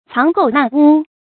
注音：ㄘㄤˊ ㄍㄡˋ ㄣㄚˋ ㄨ
讀音讀法：